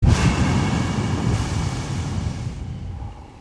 VIENTO Y MAS VIENTO
Ambient sound effects
Descargar EFECTO DE SONIDO DE AMBIENTE VIENTO Y MAS VIENTO - Tono móvil
Viento_Y_MAS_Viento.mp3